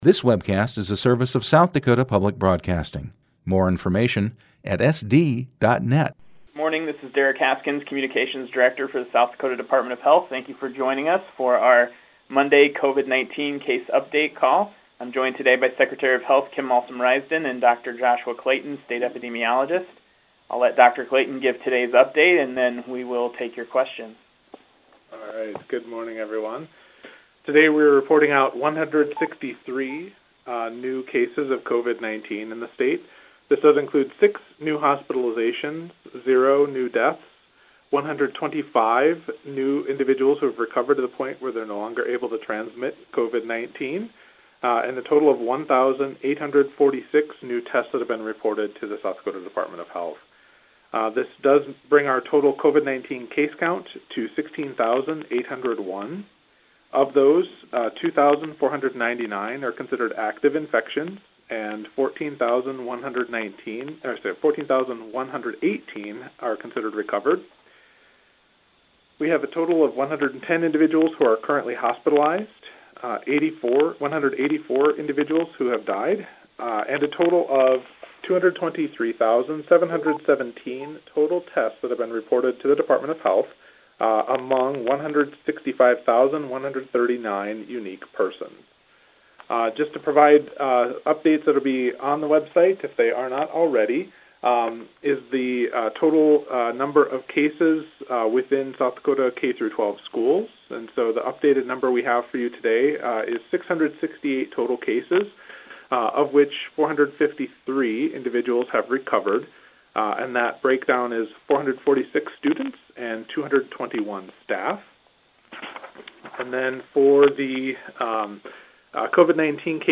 9/14/2020 State Health Officials Press Conference Regarding Coronavirus In South Dakota